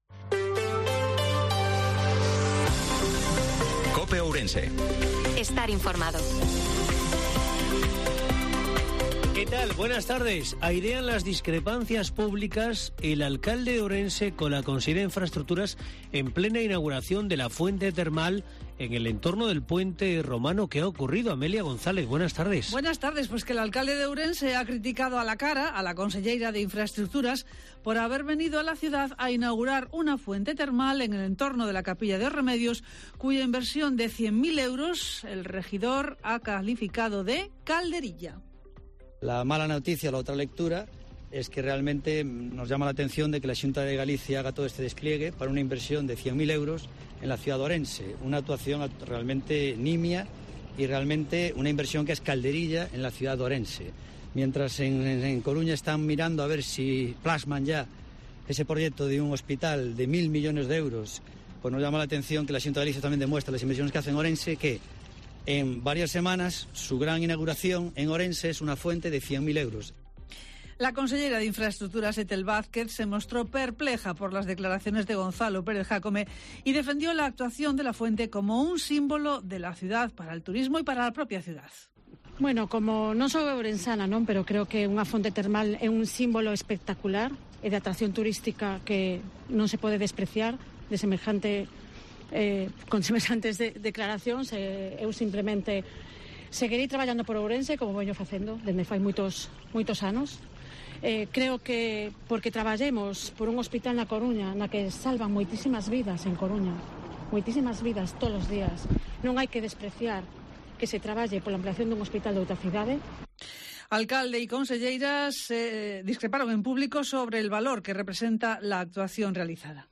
INFORMATIVO MEDIODIA COPE OURESE-20/03/2023